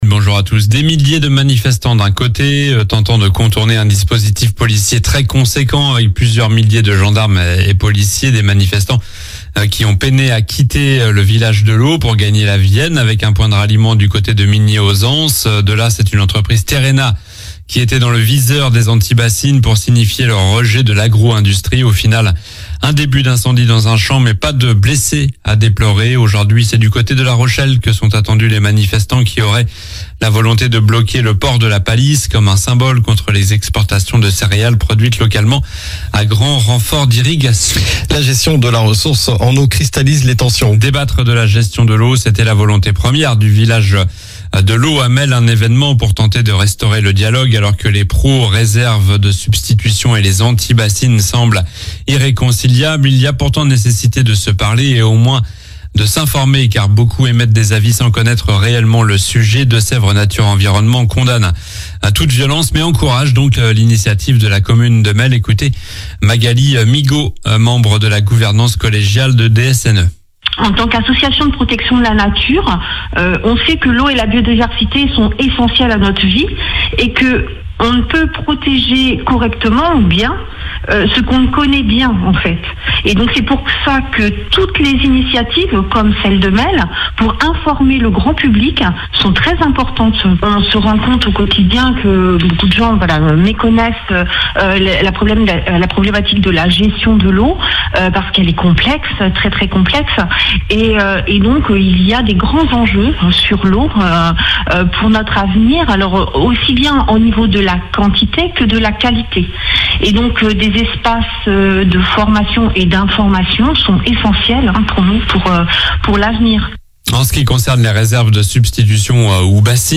Journal du samedi 20 juillet (matin)